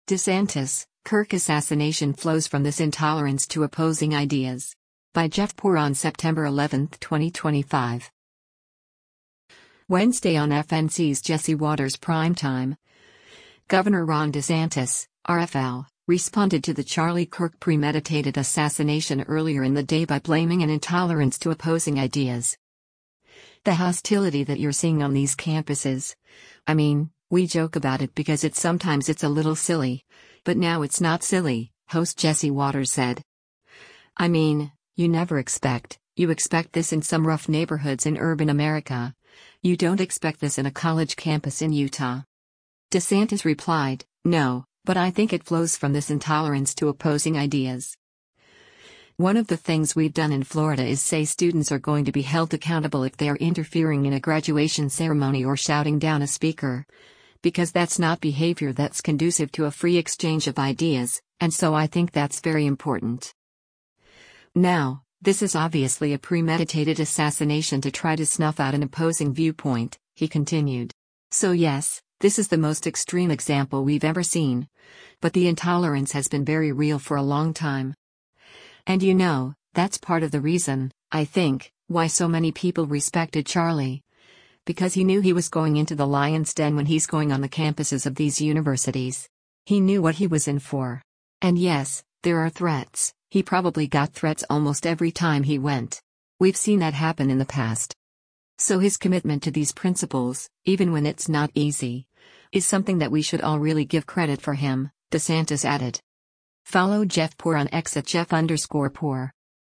Wednesday on FNC’s “Jesse Watters Primetime,” Gov. Ron DeSantis (R-FL) responded to the Charlie Kirk “premeditated assassination” earlier in the day by blaming an “intolerance to opposing ideas.”